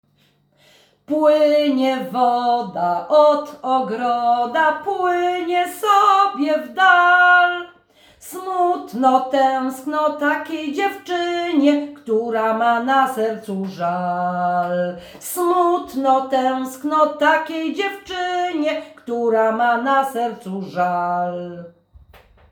Płynie woda do ogroda – Żeńska Kapela Ludowa Zagłębianki
Nagranie współczesne